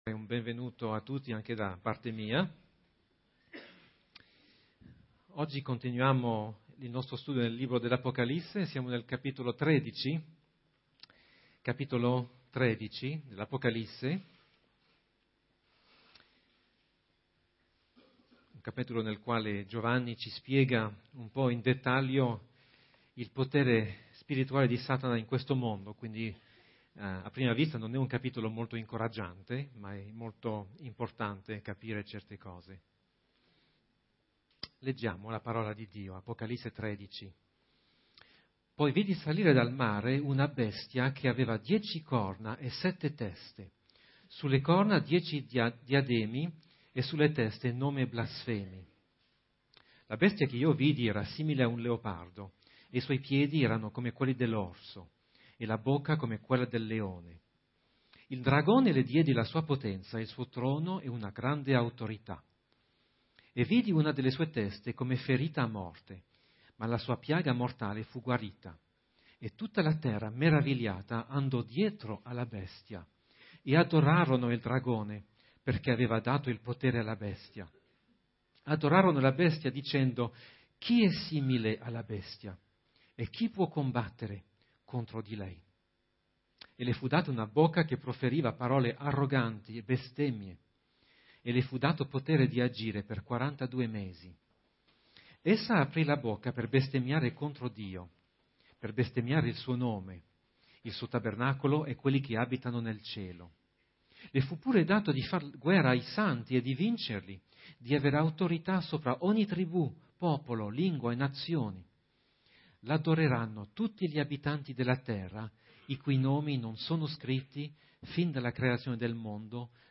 Home » Predicazioni » Apocalisse » In che mondo viviamo?